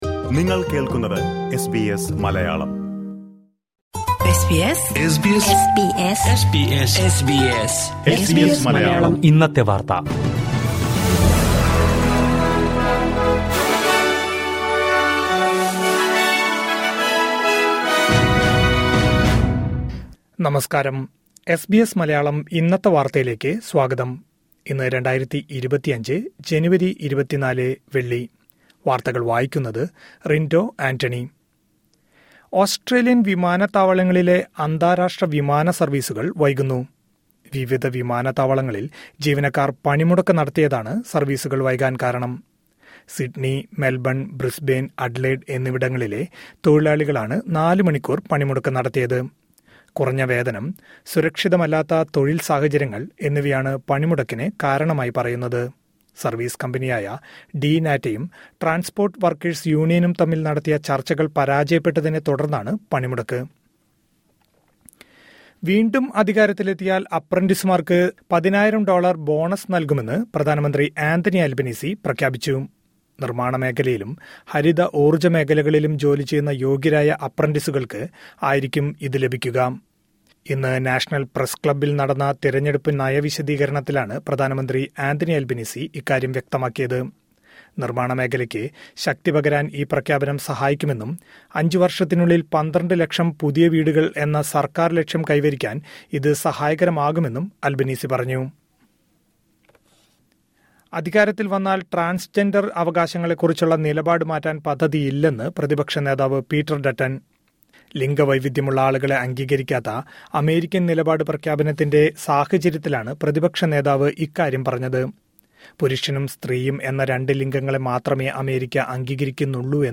2025 ജനുവരി 24ലെ ഓസ്‌ട്രേലിയയിലെ ഏറ്റവും പ്രധാന വാര്‍ത്തകള്‍ കേള്‍ക്കാം...